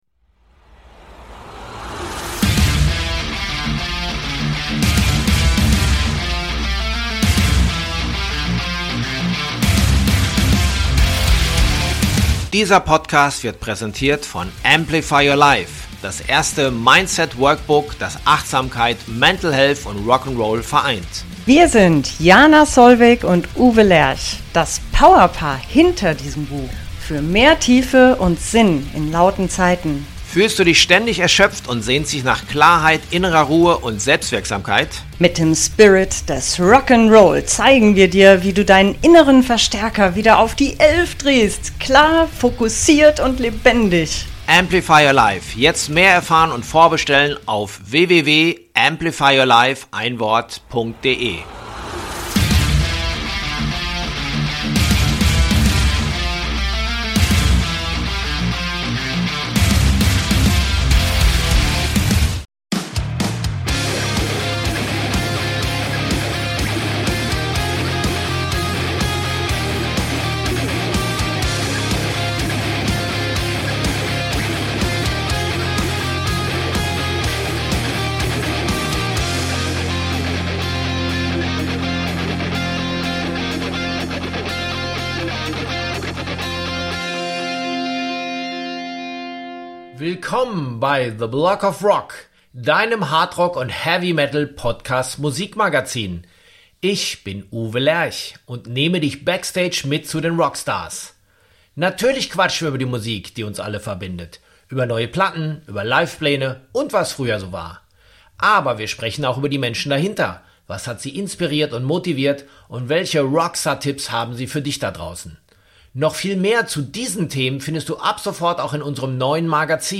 zum ausführlichen Plausch in die Studios 301 in Frankfurt ein. Wir blickten gemeinsam auf die wilden Achtziger!